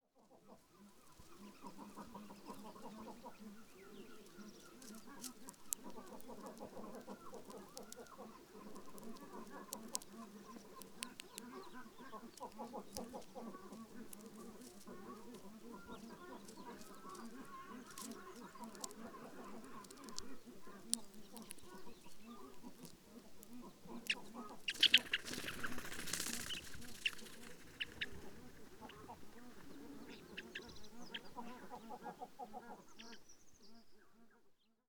PFR09323, (Sushkin’s) Asian Rosy-finch, foraging and take-of calls
Bayankhongorijn Khukh Nuur, Mongolia